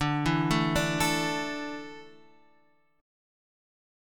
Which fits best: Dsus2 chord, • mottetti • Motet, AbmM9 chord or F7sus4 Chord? Dsus2 chord